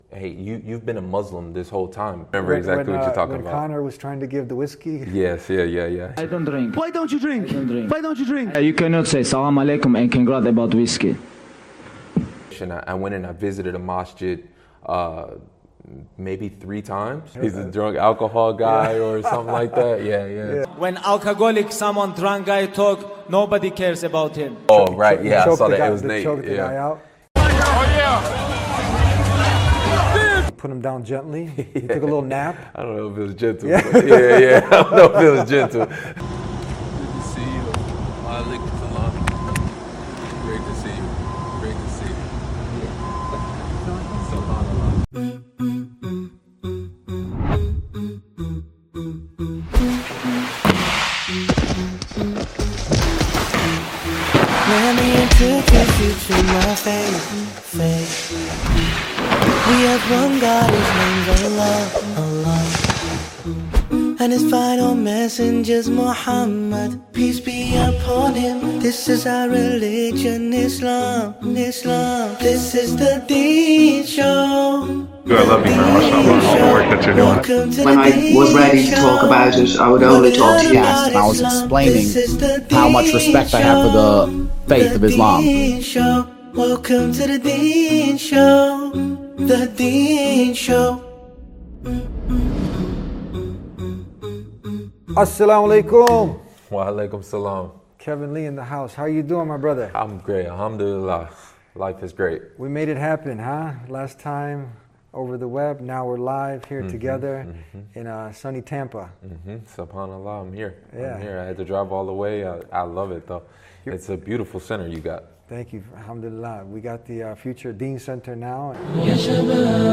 When UFC fighter Kevin Lee sat down for his first in-depth, in-person interview about accepting Islam, the raw honesty of his journey resonated far beyond the world of MMA.